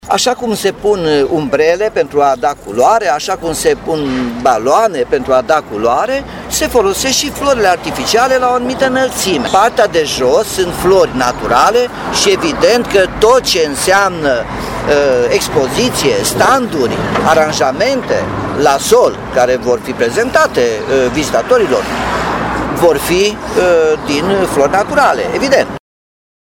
Primarul Nicolae Robu susține că aceasta este singura soluție, dar dă asigurări că toate standurile din cadrul festivalului vor avea flori naturale: